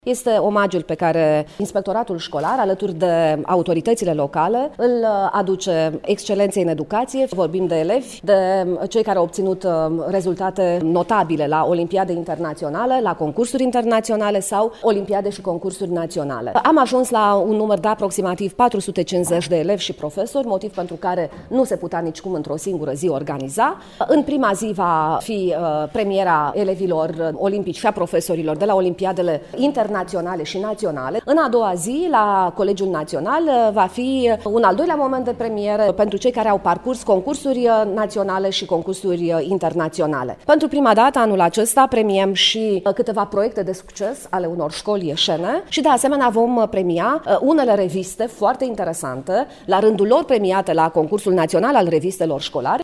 Inspectorul şcolar general, Camelia  Gavrilă: